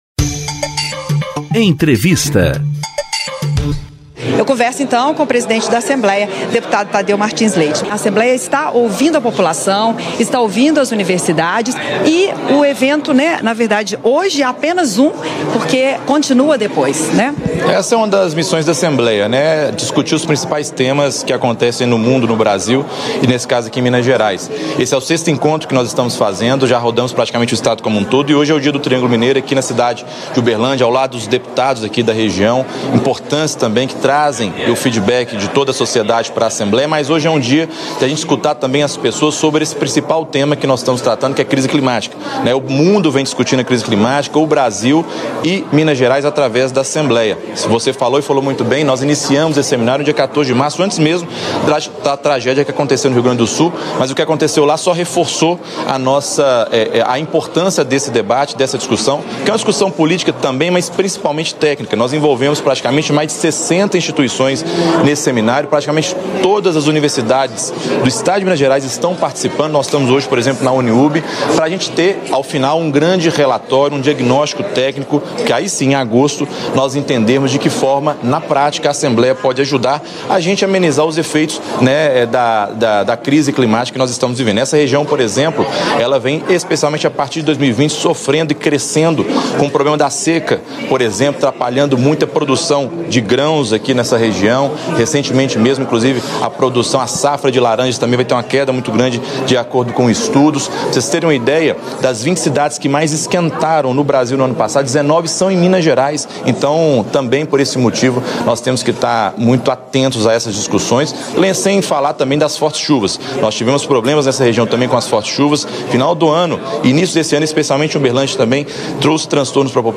Na íntegra da entrevista coletiva, nesta segunda-feira (17/6), em Uberlândia, o Presidente da Assembleia Legislativa destaca o impacto da seca na produção agropecuária da região.